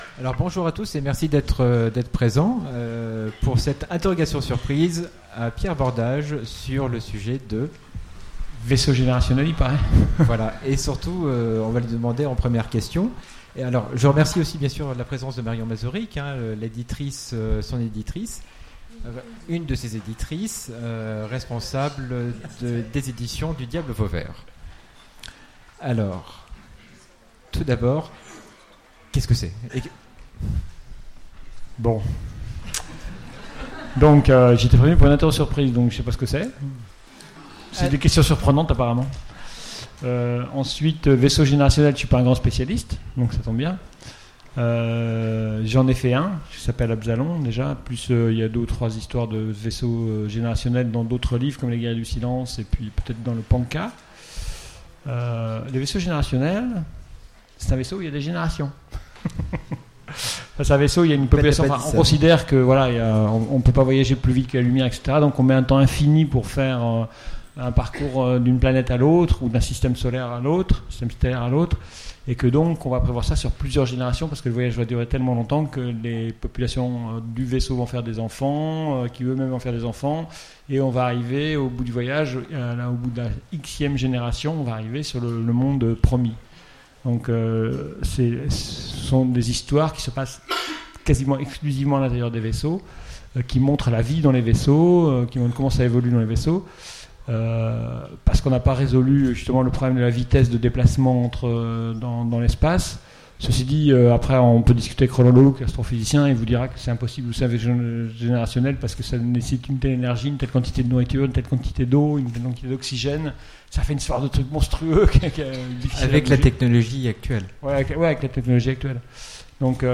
- le 03/11/2017 Partager Commenter Utopiales 2017 : Interro surprise : Les vaisseaux générationnels Télécharger le MP3 à lire aussi Pierre Bordage Genres / Mots-clés Espace Conférence Partager cet article